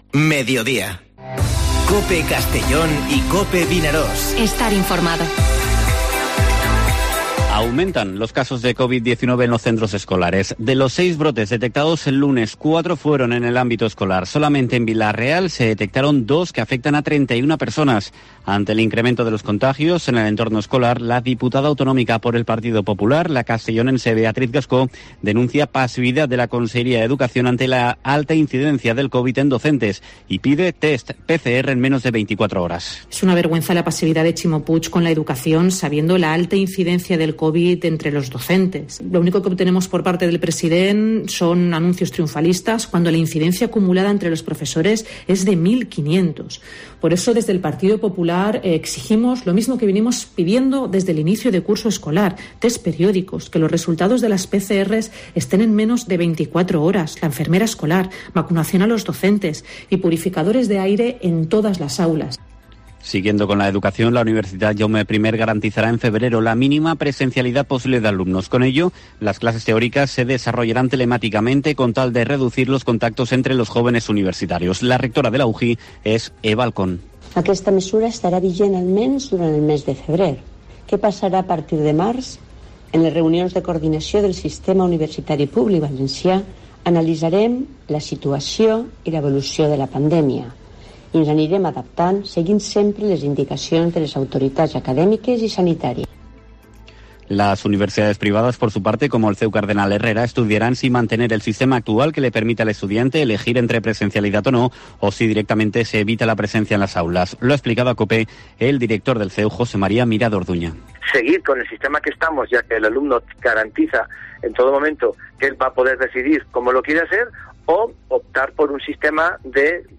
Informativo Mediodía COPE en la provincia de Castellón (27/01/2021)